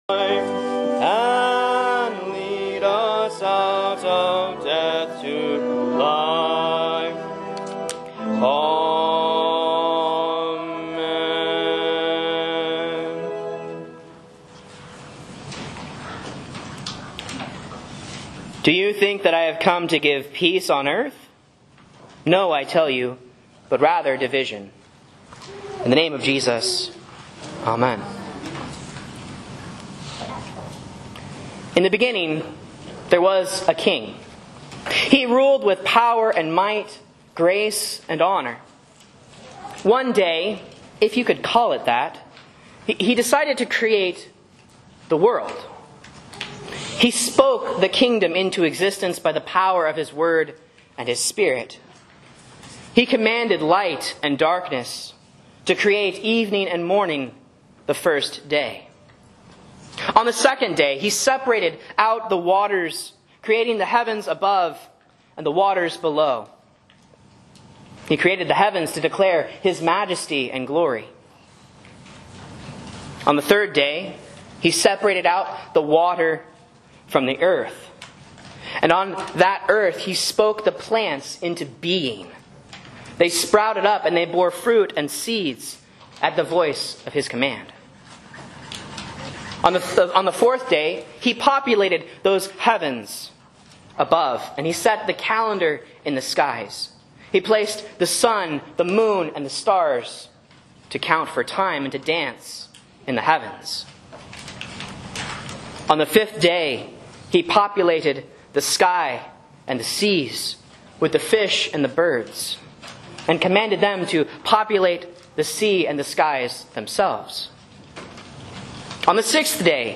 Sermons and Lessons from Faith Lutheran Church, Rogue River, OR
A Sermon on Luke 12:51 for Proper 15 (C)